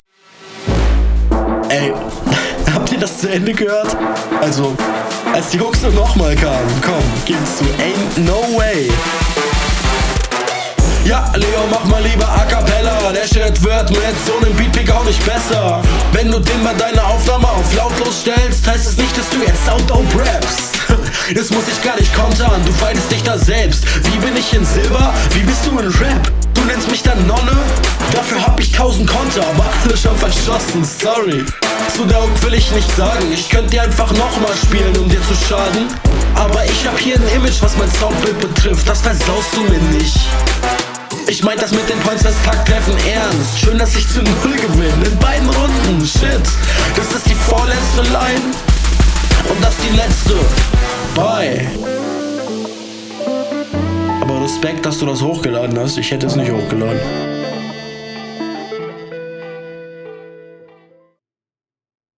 Mische nen bisschen besser, aber immernoch verbesserungswürdig.